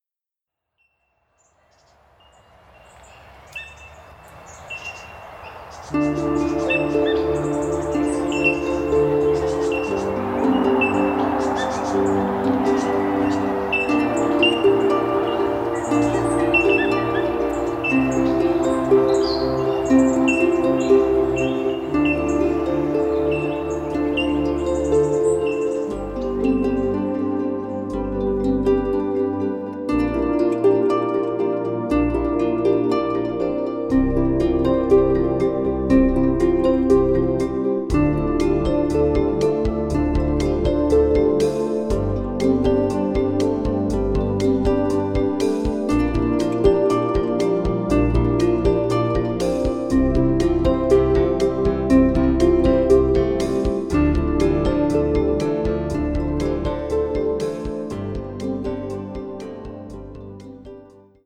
Recorded at the Royal Botanic gardens